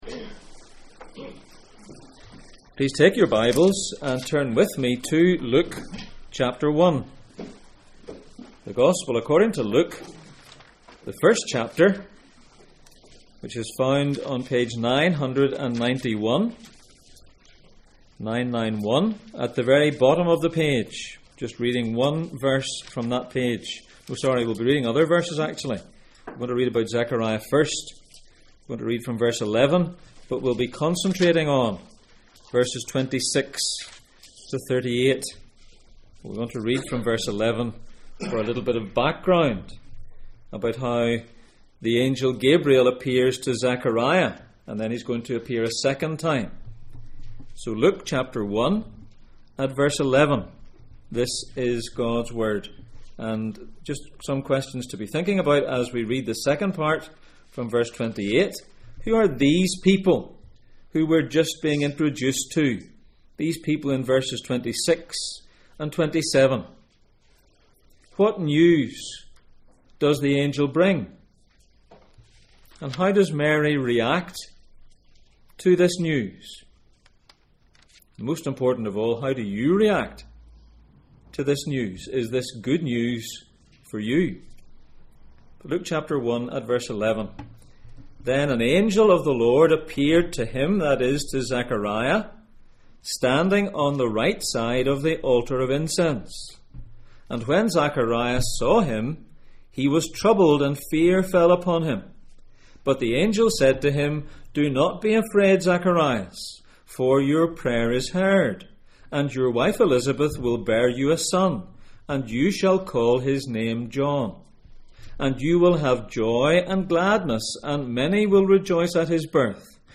God sends a Saviour Passage: Luke 1:1-38 Service Type: Sunday Morning %todo_render% « Where are you when it comes to the covenant?